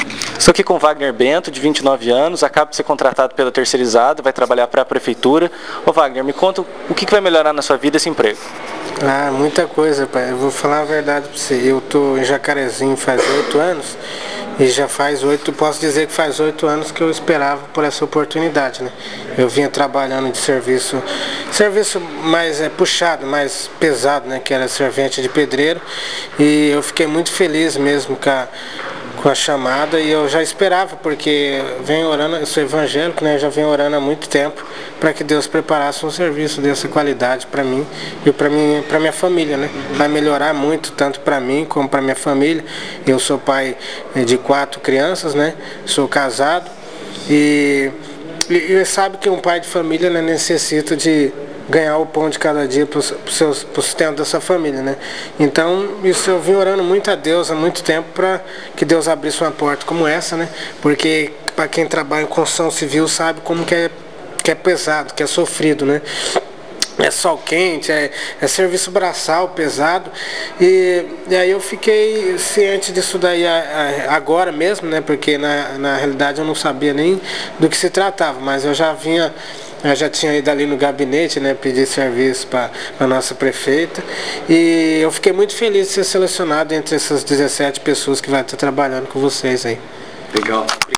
Confira depoimentos de três dos novos servidores prestadores de serviço para Educação.